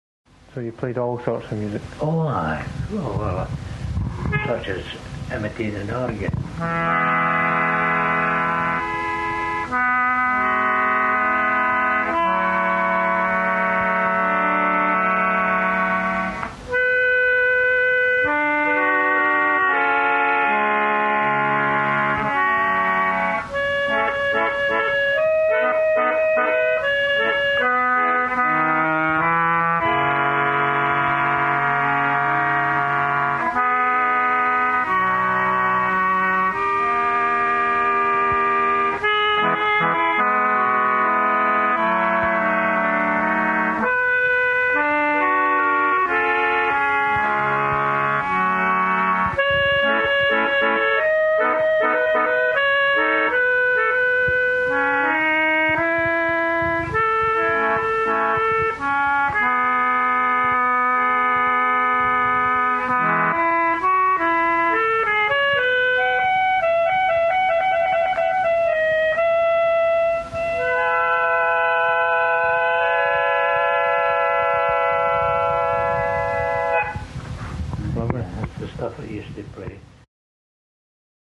Nevertheless, he gladly shared some other fragments from his once extensive repertory:
Organ Imitation